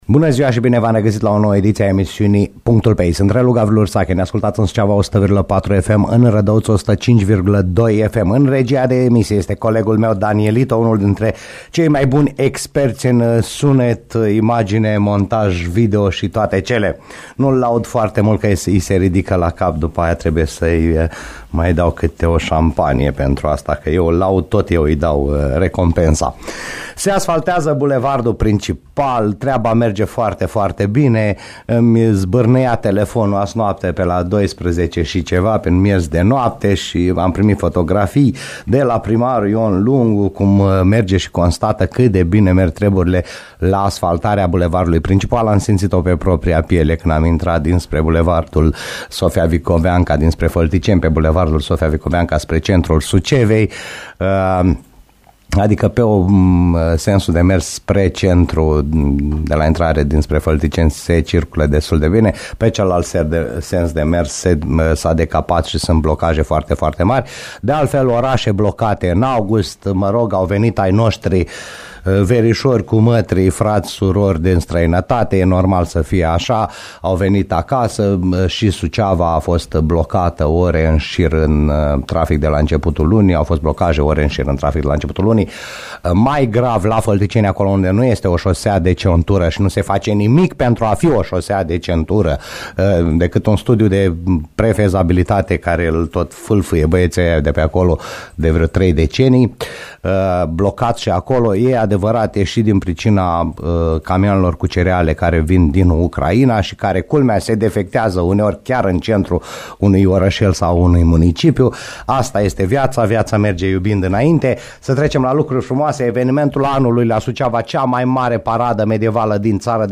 Deputatul PSD Suceava Mirela Adomnicăi a fost invitată, astăzi, la PUNCTUL PE I.